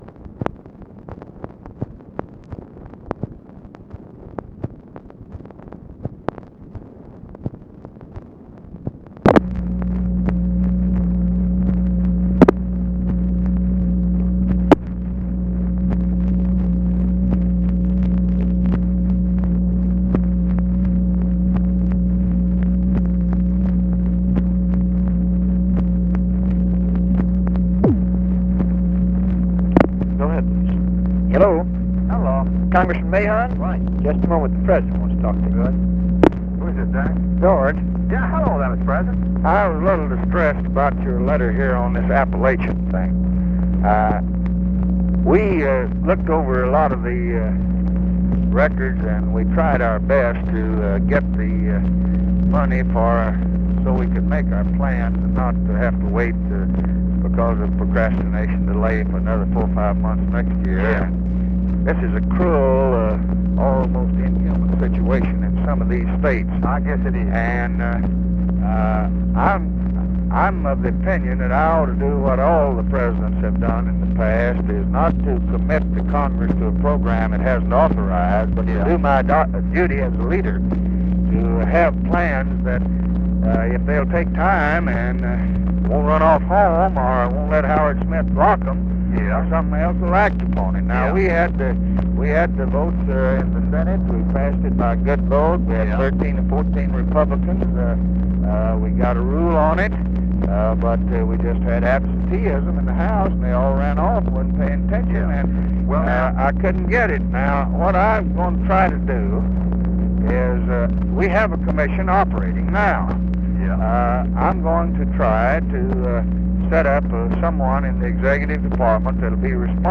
Conversation with GEORGE MAHON, JACK VALENTI and JOHN CONNALLY, October 21, 1964
Secret White House Tapes